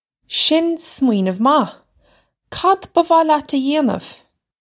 Shin smwee-nuv mah. Kad buh vah lyat uh yay-nuv?
This is an approximate phonetic pronunciation of the phrase.